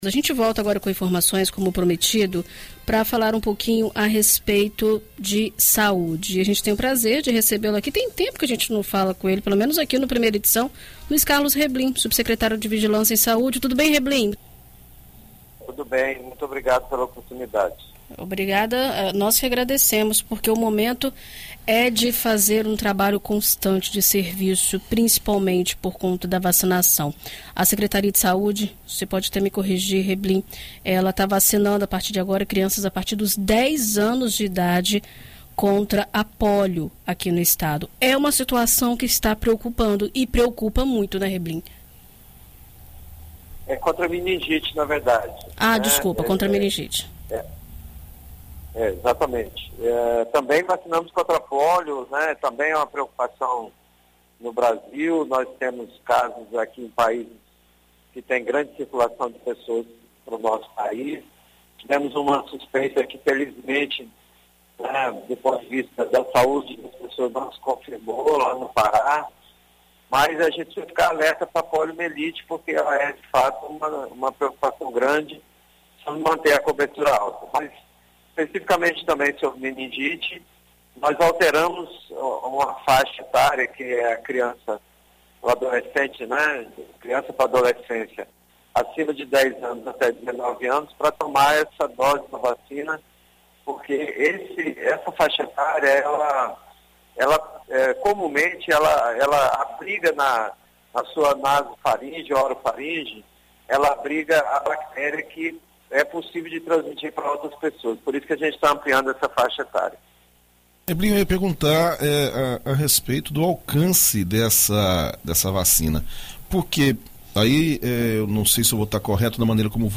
Em entrevista à BandNews FM Espírito Santo nesta segunda-feira (10), o subsecretário de Vigilância em Saúde, Luiz Carlos Reblin, explica a atual situação da enfermidade nos municípios capixabas e como a Secretaria de Saúde (Sesa) tem atuado para mitigar os impactos.